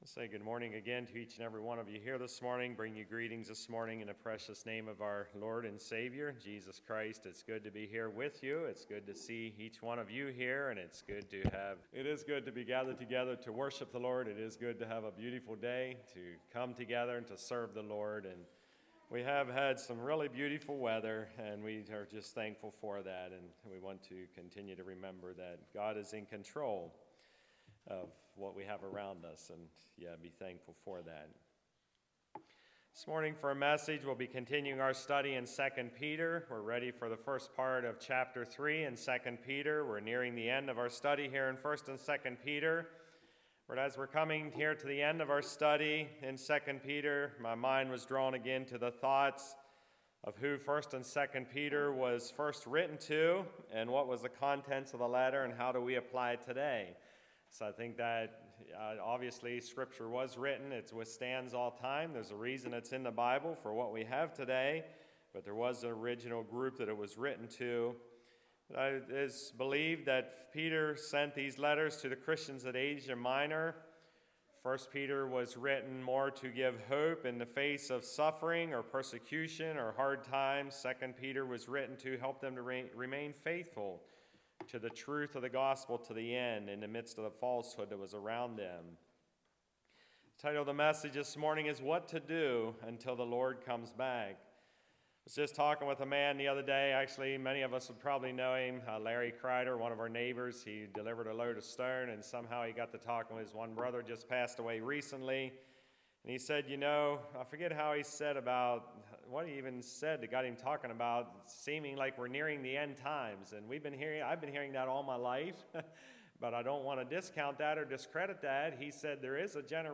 Passage: 2 Peter 3:1-9 Service Type: Message